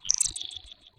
Grabcrab_pickup.ogg